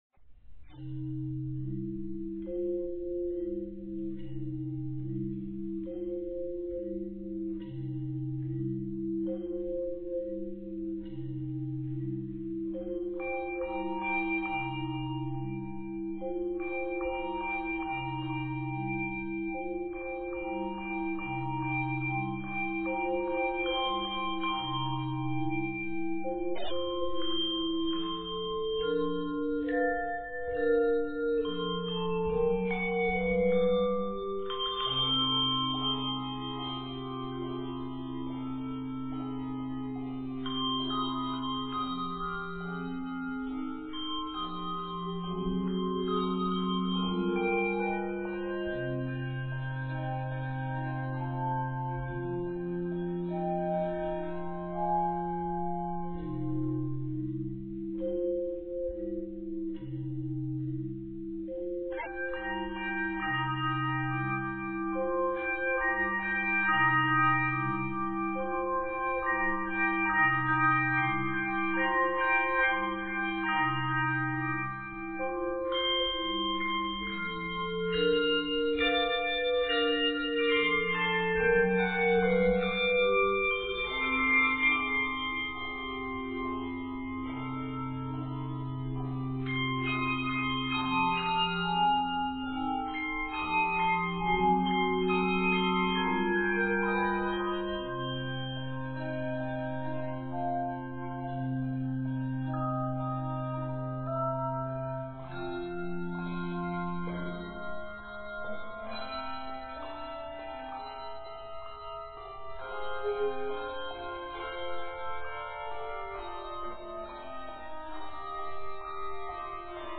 sounds both jazzy and rich on handbells.